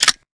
metahunt/weapon_foley_drop_22.wav at 84a4c88435ec9cf2ad6630cab57ea299670b57e2
weapon_foley_drop_22.wav